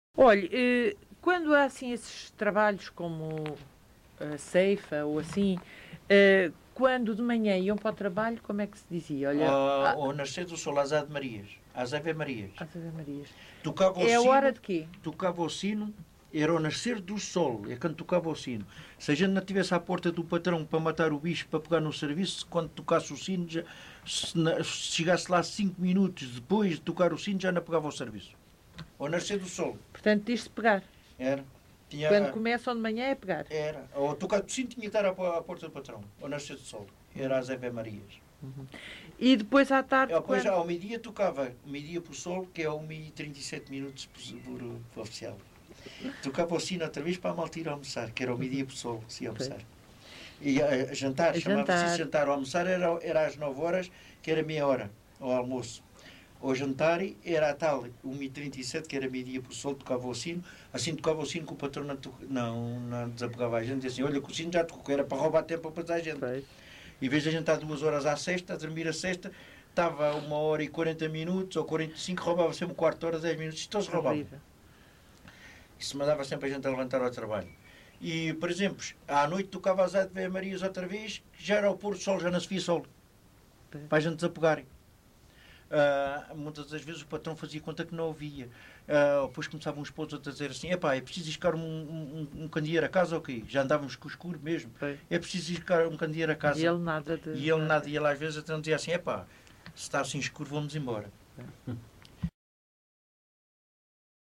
LocalidadeEnxara do Bispo (Mafra, Lisboa)